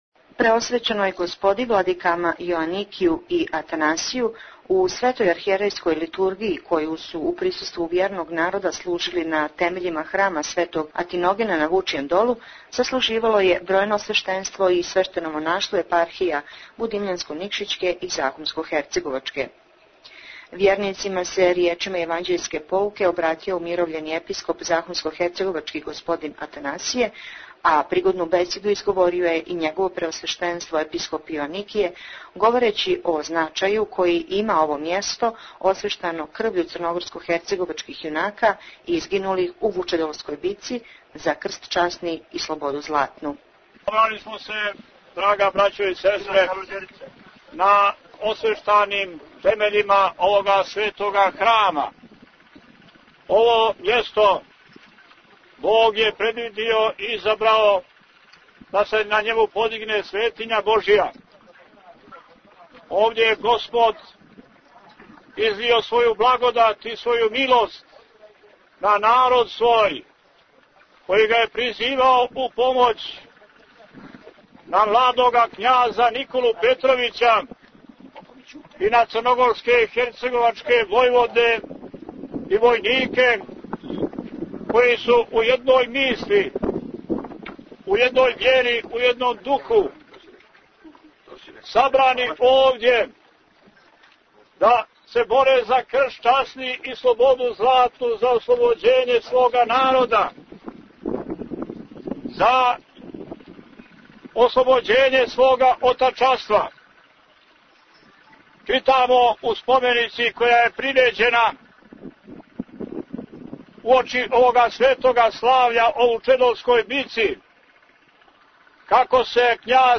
Њихова Преосвештенства Епископи будимљанско - никшићки Г. Јоаникије и умировљени Епископ захумско - херцеговачки Г. Атанасије служили су у сриједу, 29. јула 2009. на празник Светог свештеномученика Атиногена, Свету архијерејску Литургију на темељима храма у изградњи Светог Атиногена на Вучјем долу. Након Светог богослужења одржан је црквено - народно сабрање, чиме је, у организацији Епархија будимљанско - никшићке и захумско - херцеговачке, обиљежено 133. године славне Вучедолске битке.
Tagged: Извјештаји